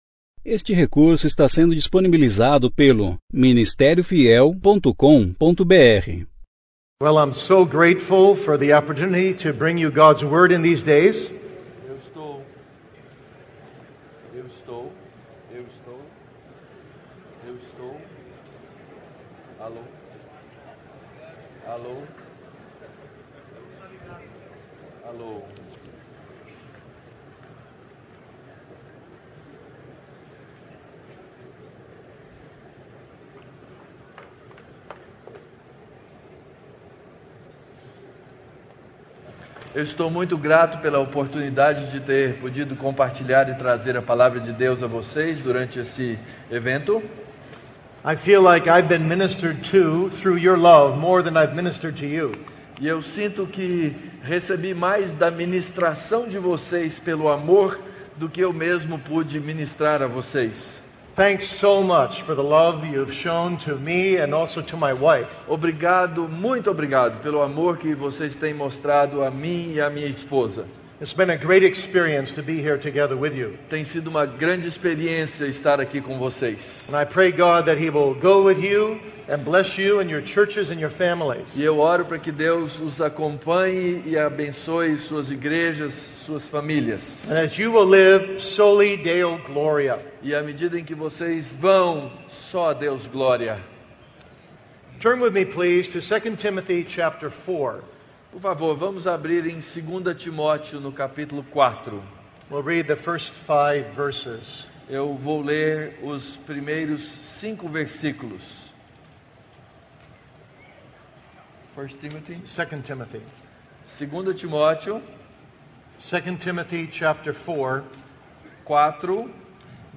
Conferência: 28ª Conferência Fiel para Pastores e Líderes Tema: Alicerces da Fé Cristã - O que me to